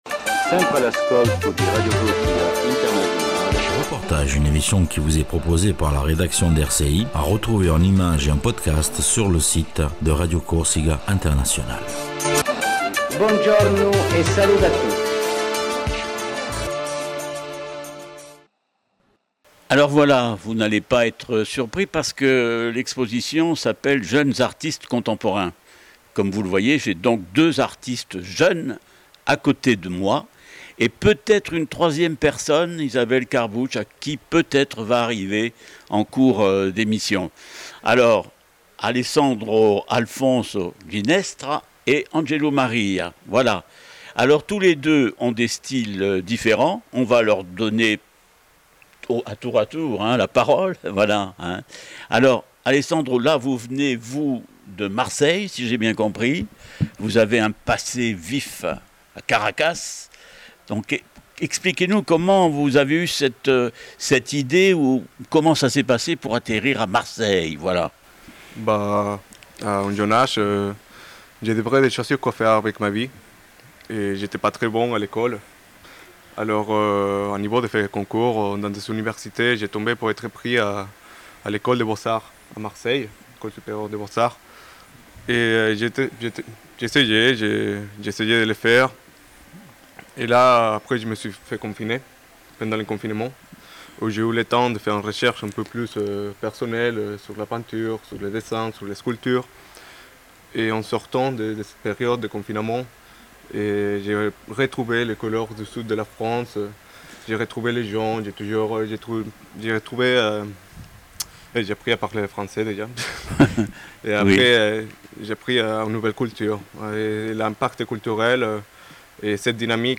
REPORTAGE GALERIE NOIR ET BLANC SUR LES JEUNES ARTISTES